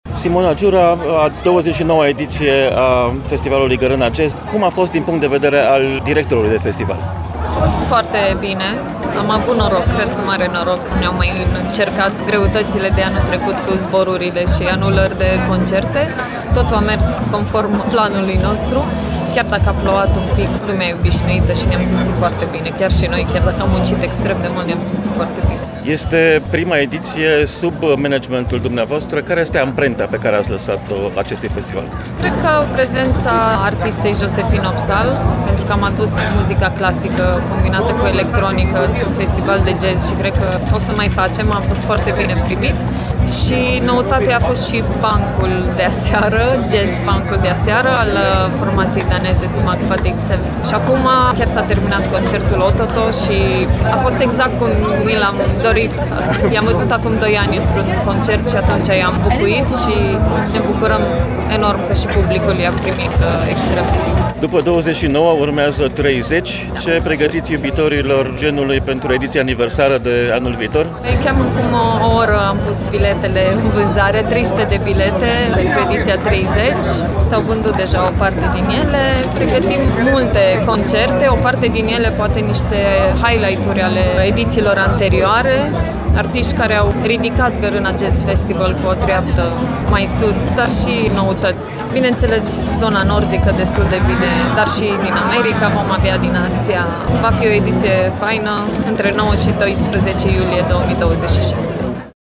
INTERVIU | Gărâna Jazz Festival 2026 a pornit la drum.
Un interviu